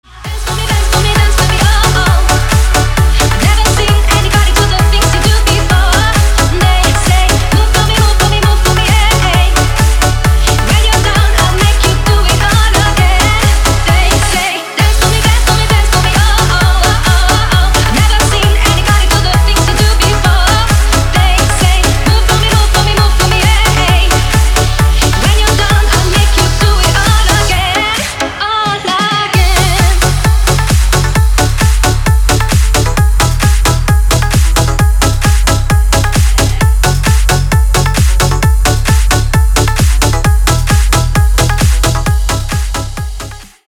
женский вокал
remix
зажигательные
электронная музыка
быстрые
house
динамичные
подвижные
для тренировок